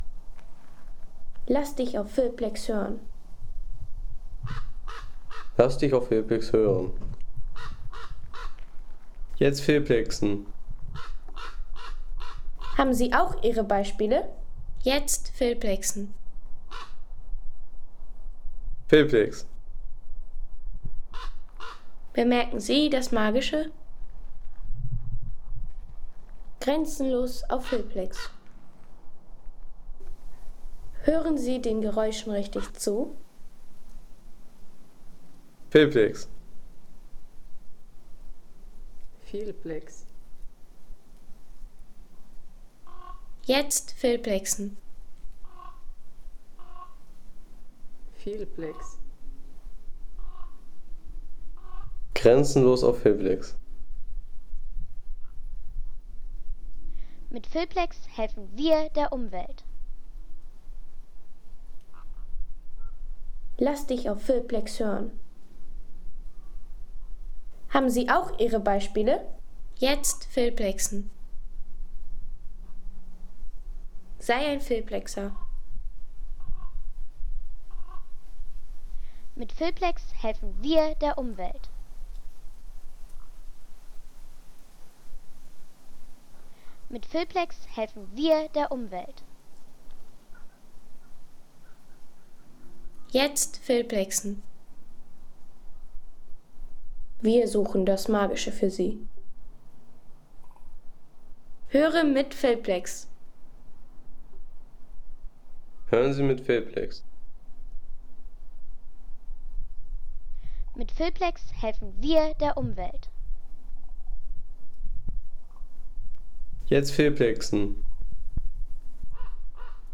Kolkraben auf Monterfarno
Kolkraben auf Monterfarno Home Sounds Tierwelt Vögel Kolkraben auf Monterfarno Seien Sie der Erste, der dieses Produkt bewertet Artikelnummer: 26 Kategorien: Tierwelt - Vögel Kolkraben auf Monterfarno Lade Sound.... Beim Wandern in den Bergen flog ein schwarzer Vogel über mir hinweg.